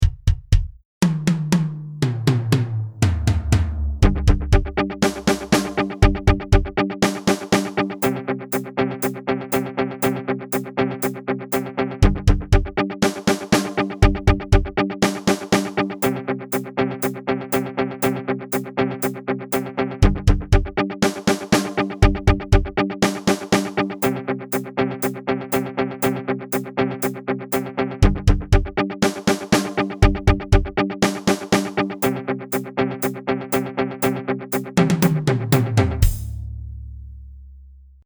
• An audio backing track to play along with in class